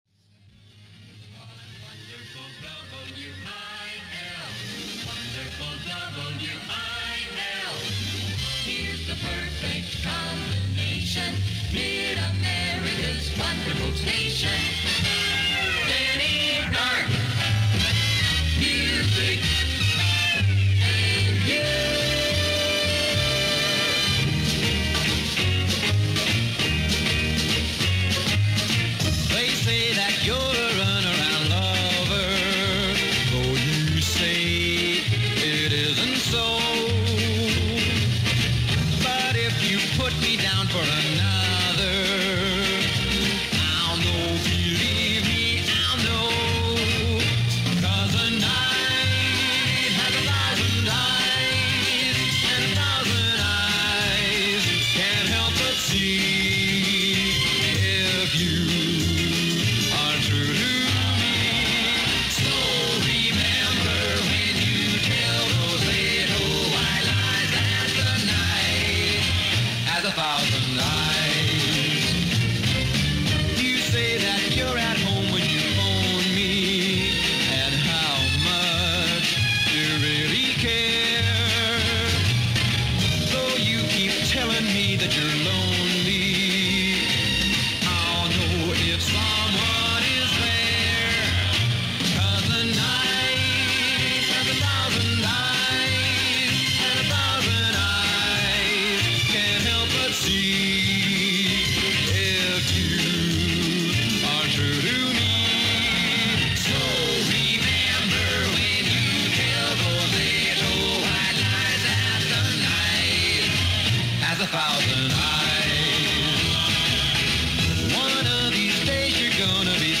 1. Early Beginnings: Danny Dark started as a radio DJ in the late 1950s while studying at Drury University. His early work laid the groundwork for his smooth, engaging style.
Audio Digitally Remastered by USA Radio Museum
WIL-AM-St.-Louis-Danny-Dark-January-4-1963.mp3